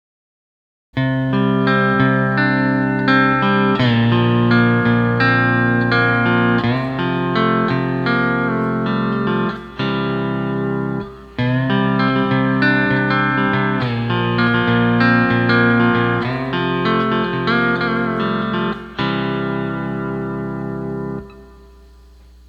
With RKLF you can get a nice low drone effect on the 10th string at an A note while having all the other pedal down notes available.
MSA Legend D-10 Tuning
RKLF Only - Low Drone - Nice Intro Or Guitar type backing riff